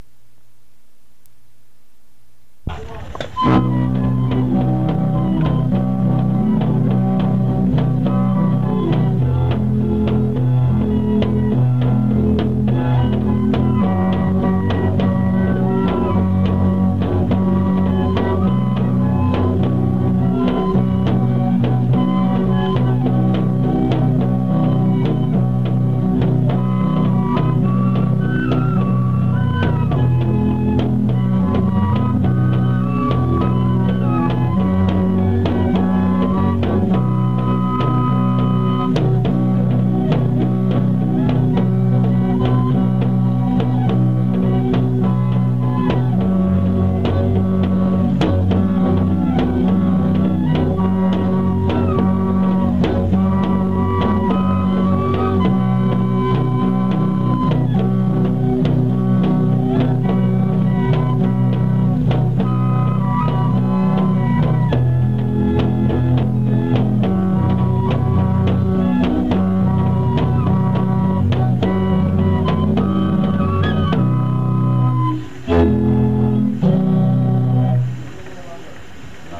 Di halaman ini ada file dengan musik bambu. Satu kelompok direkam di Petimbelagi pada bulan April 1999 sementara mereka melati untuk peluncuran Alkitab yang dilaksanakan pada bulan Juni tahun itu.
Kelompok kedua direkam di Rejeki pada bulan April 1990.